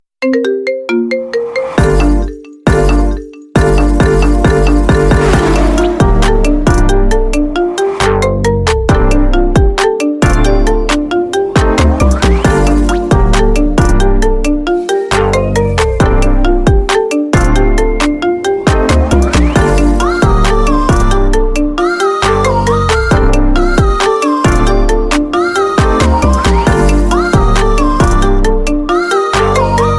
Emotional Speed Rhythm